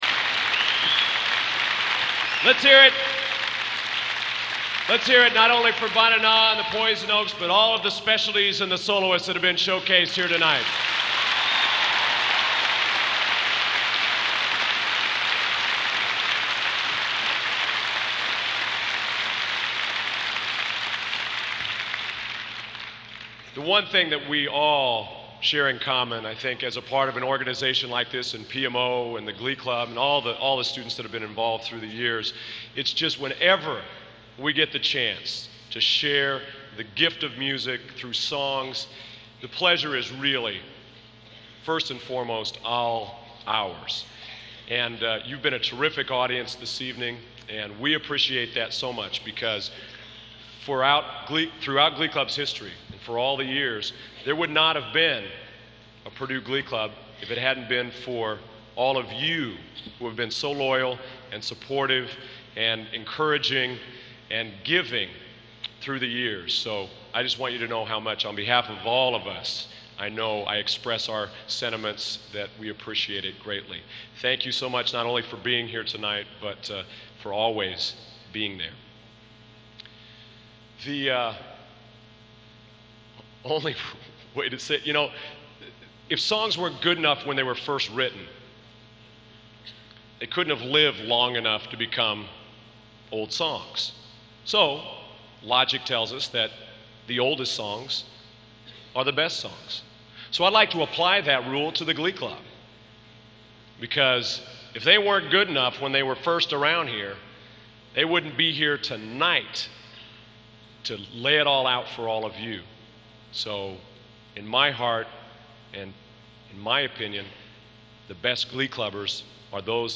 Collection: Centennial Celebration Concert 1993
Genre: | Type: Director intros, emceeing